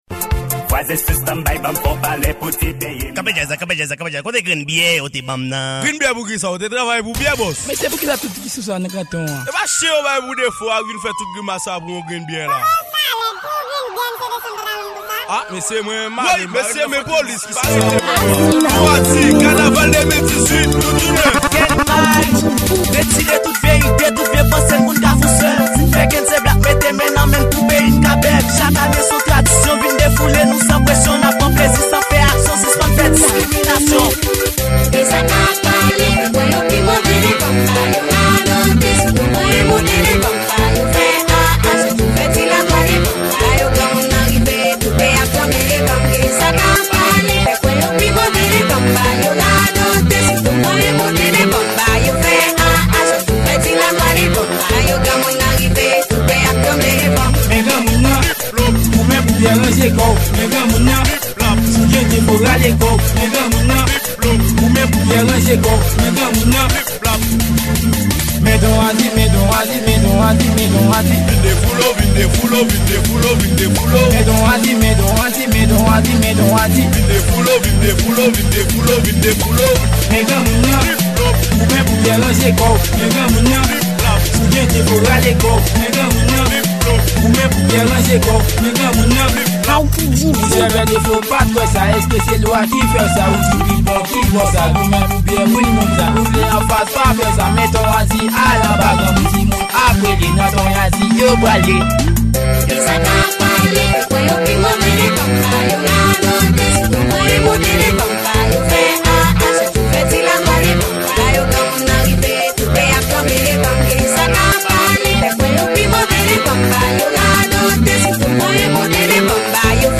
Genre: Kanaval